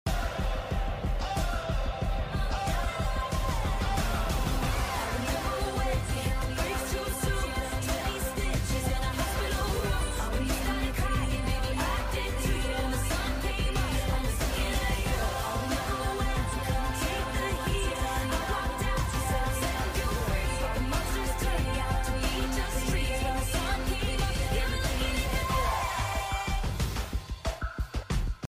overlapped edit audio V2